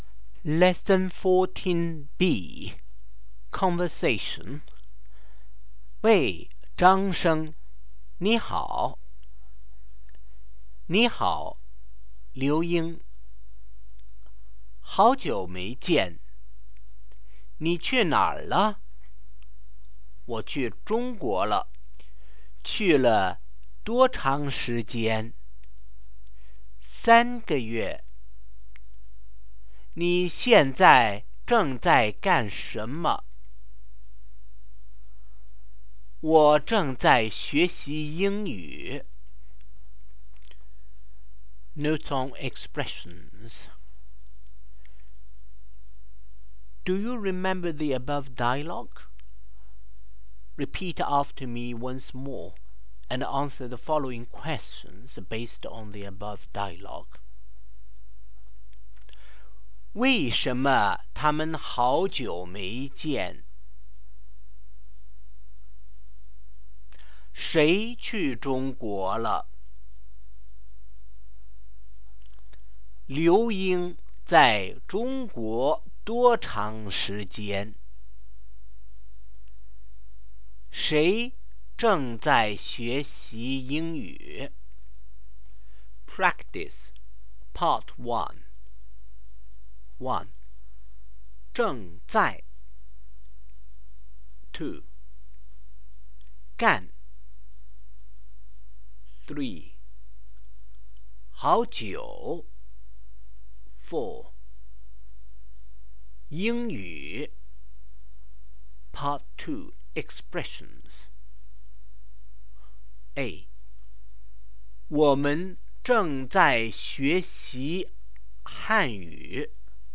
Conversation
Tape version